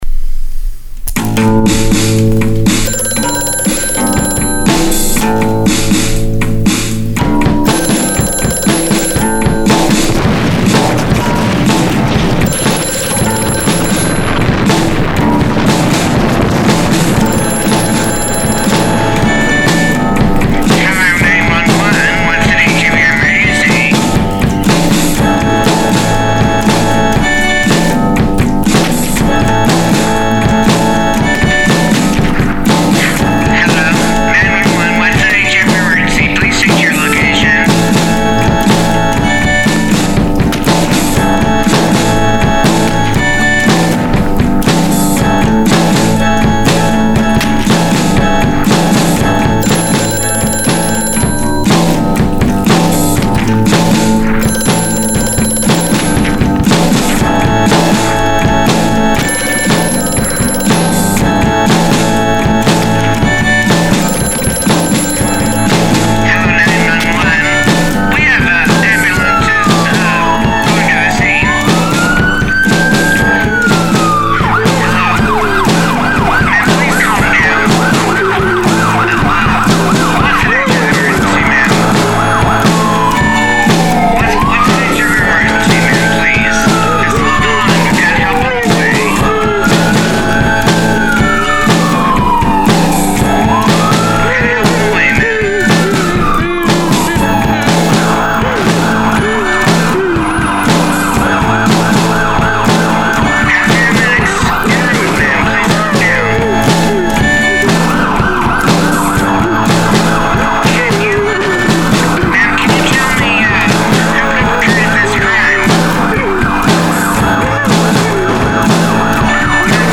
Ma’am, What is the Nature of Your Emergency? (original soundscape/art)